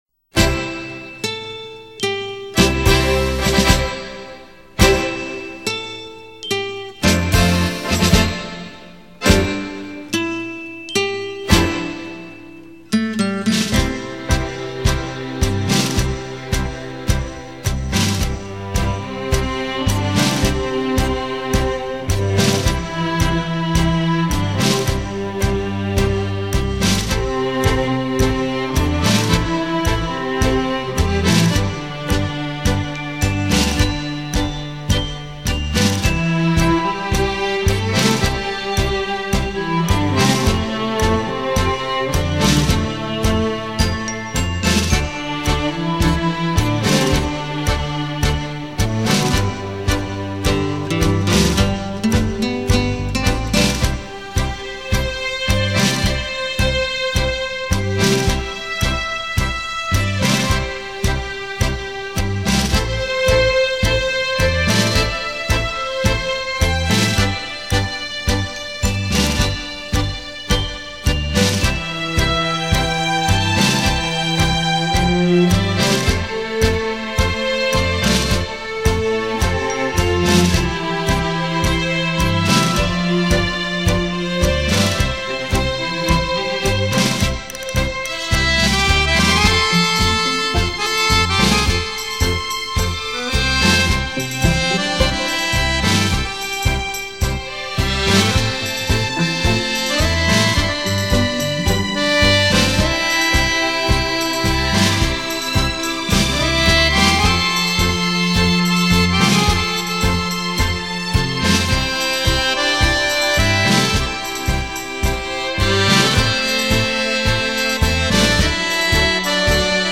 优美的探戈旋律让人“醉”入其中．．． 　.